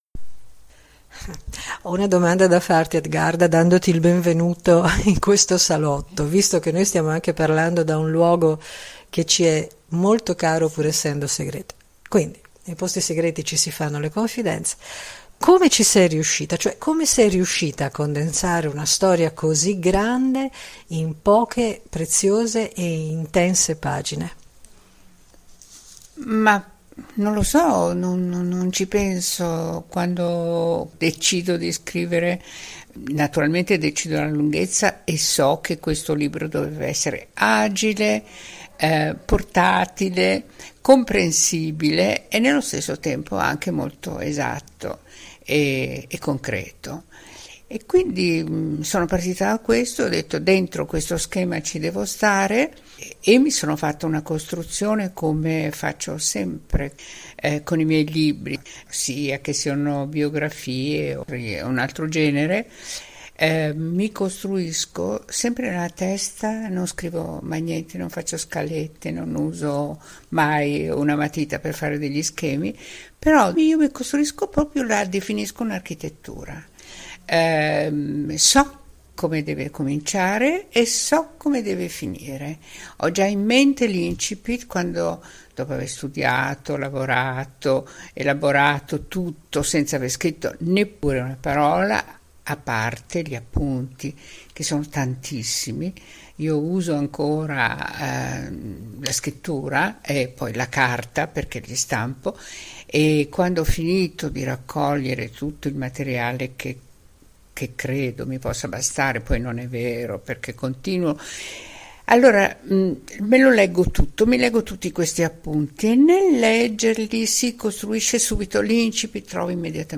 chiacchierata